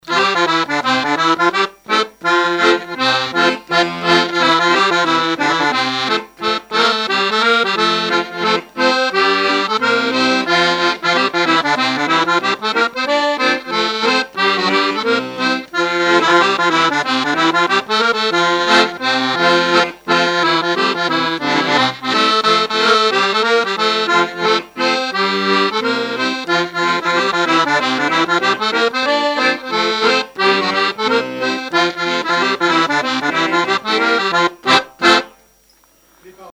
Saint-Christophe-du-Ligneron
Chants brefs - A danser
danse : scottich sept pas
Pièce musicale inédite